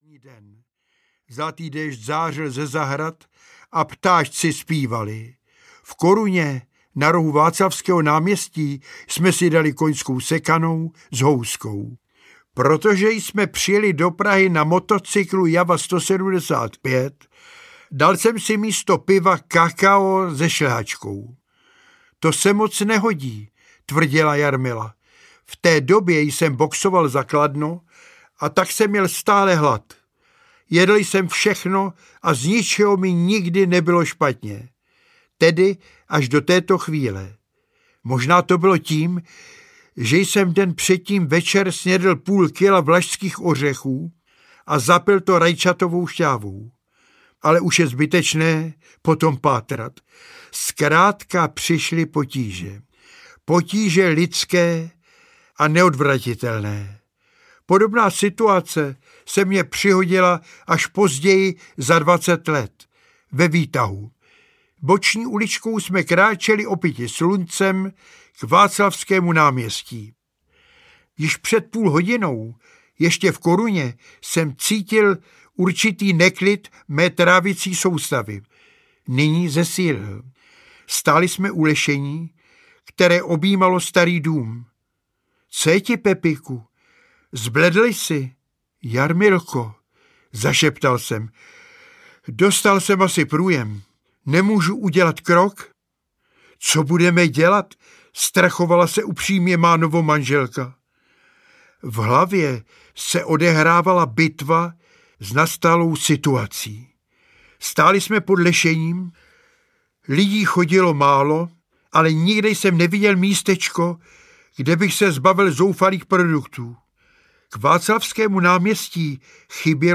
Audiokniha Pojďte se smát je autorské čtení Josefa Fouska.
Ukázka z knihy
• InterpretJosef Fousek